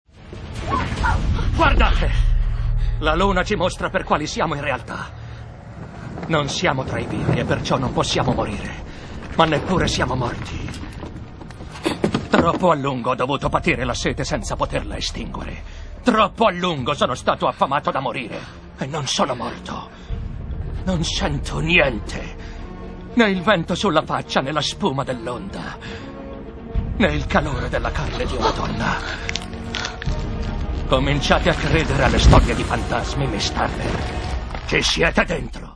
in cui doppia Geoffrey Rush.